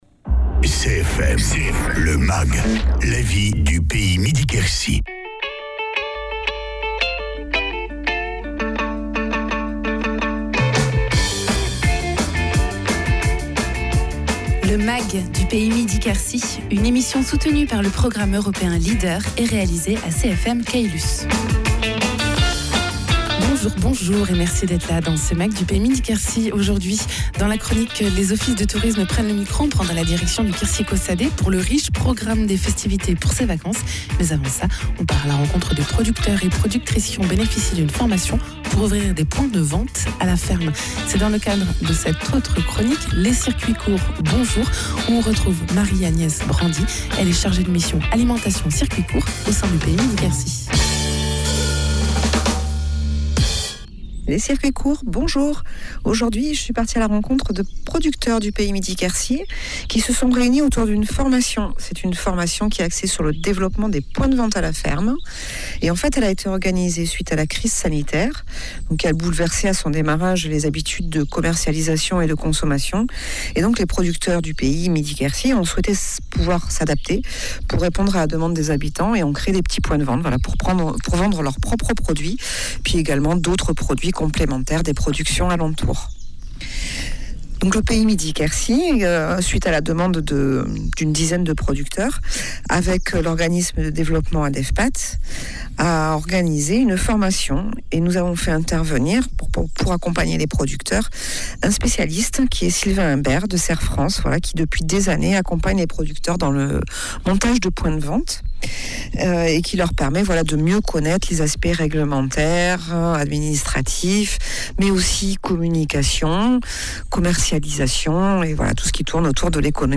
Reportage en circuits courts avec les participants à une formation